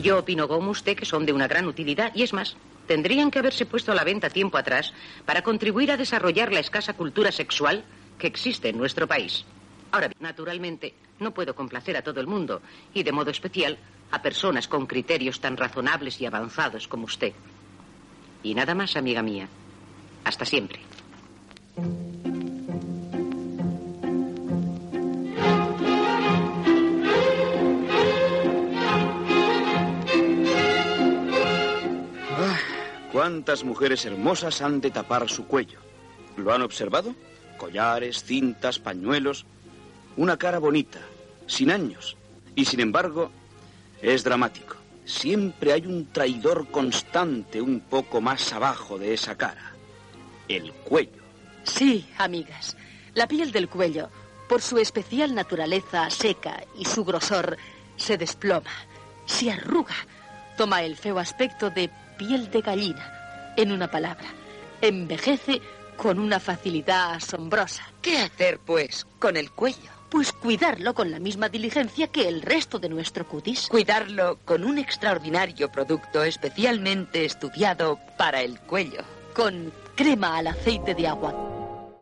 Final de la resposta a una carta i publicitat